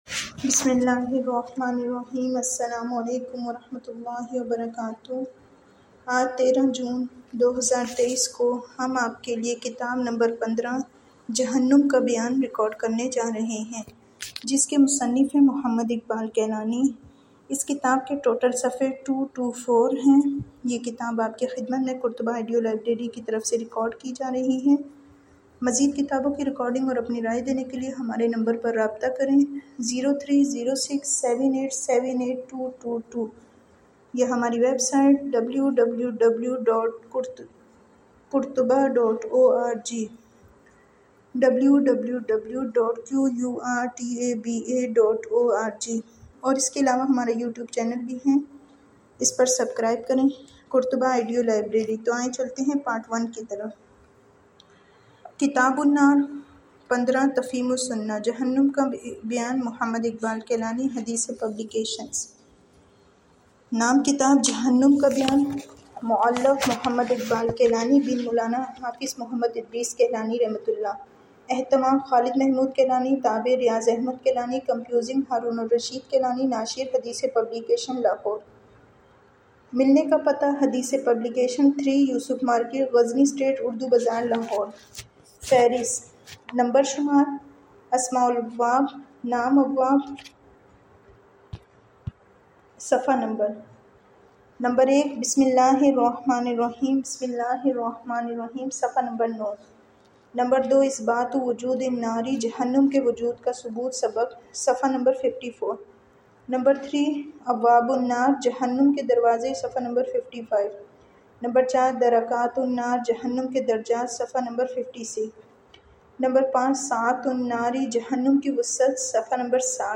Listen the first part of the audio book below as a demo and if you like the book go ahead and add this audio book into your weekly reading list by pressing the link below the audio player and then go to your reading list and download the audio book Download demo